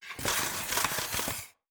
Skates and Ice Sharp Turn.wav